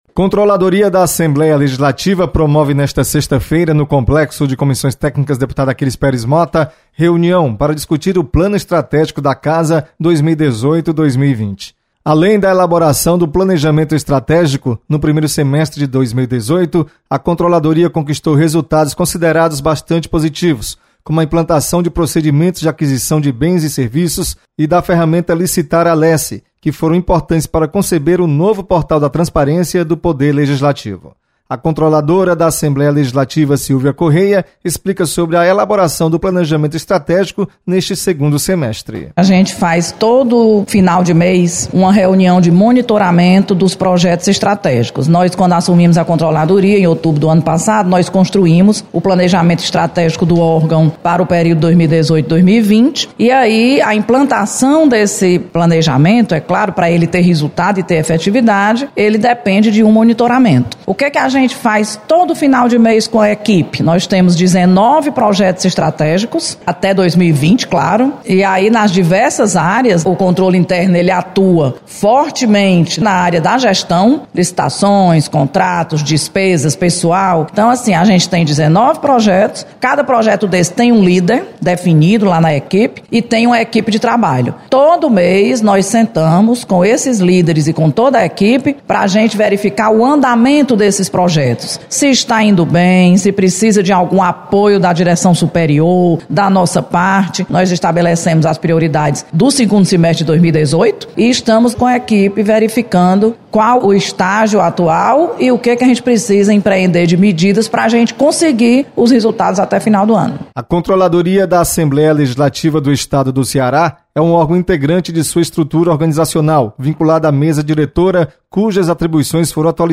Você está aqui: Início Comunicação Rádio FM Assembleia Notícias Controladoria